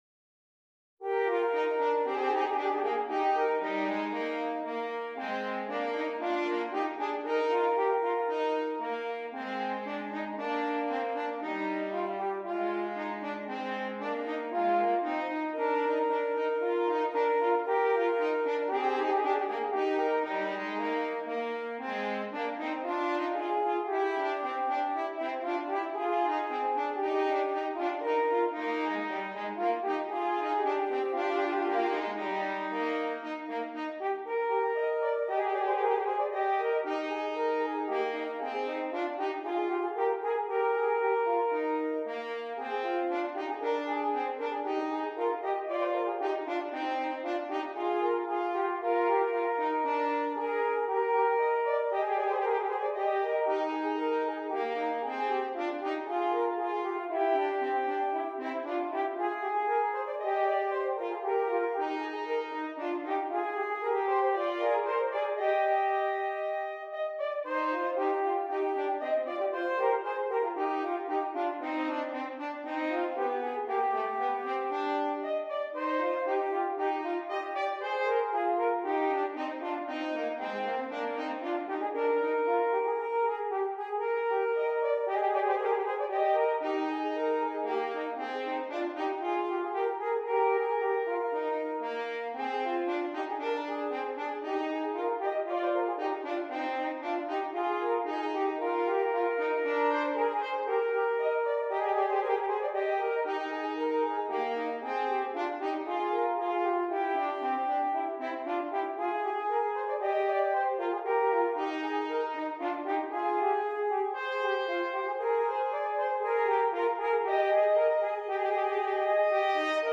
Brass Band
2 F Horns